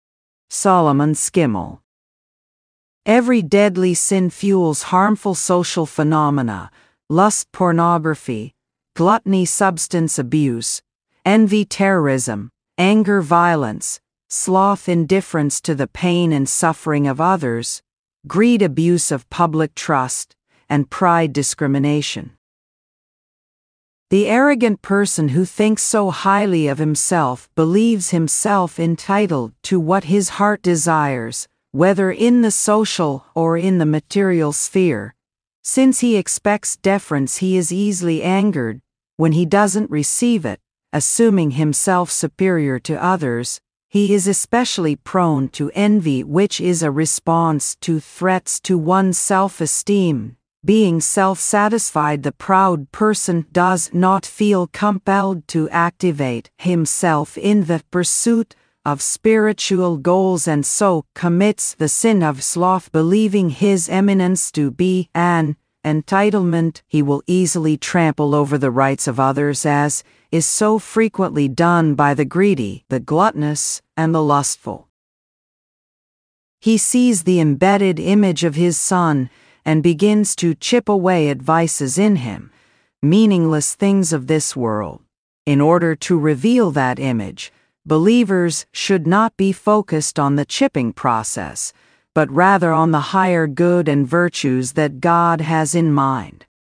Text to Speech From Unrealspeech